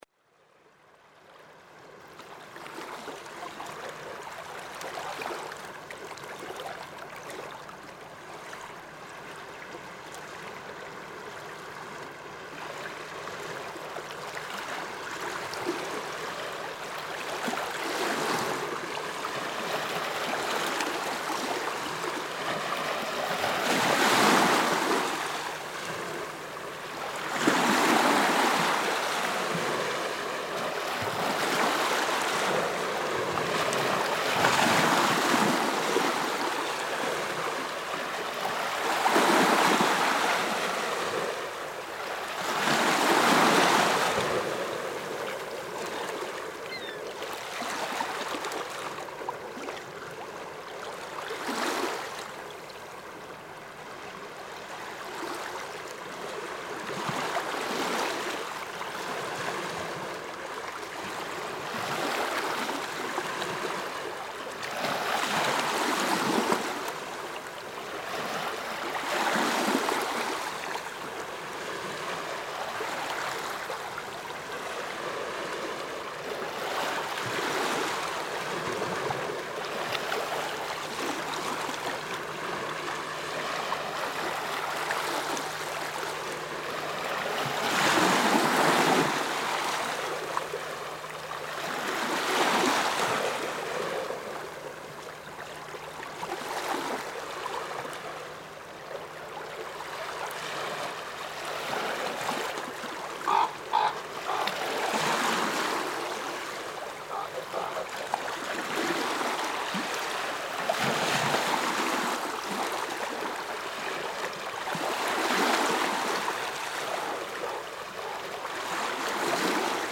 Seashore – HLJÓÐMYND – SOUNDIMAGE
waves-flateyri-final.mp3